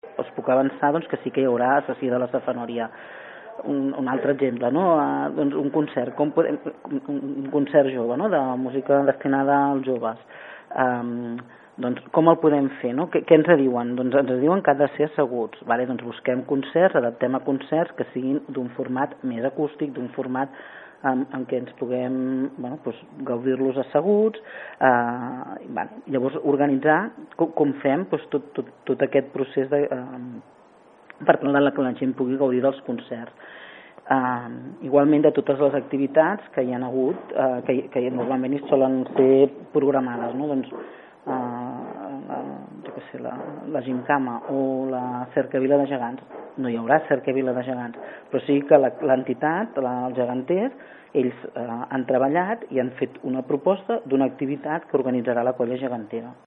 En una entrevista a l’Informatiu de Ràdio Palafolls, la regidora de Cultura i Festes de l’Ajuntament, Susanna Pla, ha assegurat que, si no hi ha una evolució negativa de la situació, els actes podran tirar endavant també amb les noves mesures anunciades pel govern, ja que les activitats s’han dissenyat en base a les actuals circumstàncies.